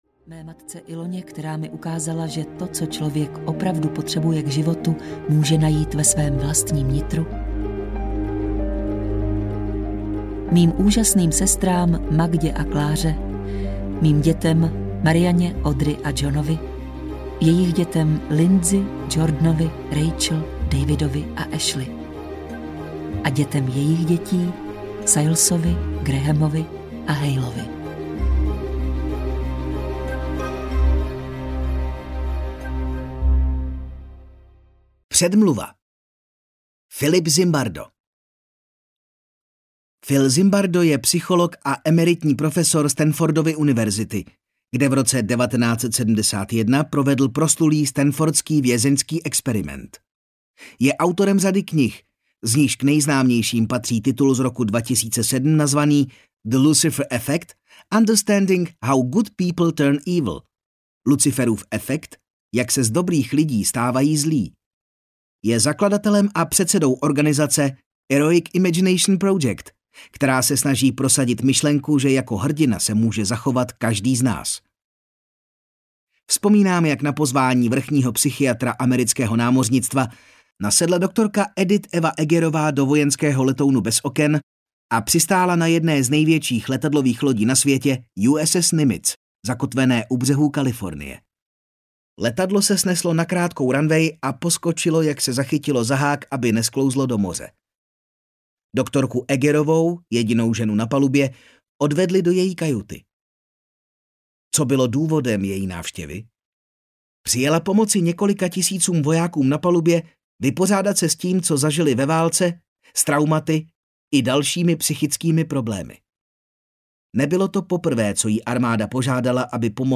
Máme na vybranou audiokniha
Ukázka z knihy